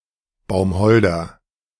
Baumholder (German pronunciation: [baʊmˈhɔldɐ]